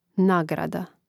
nȁgrada nagrada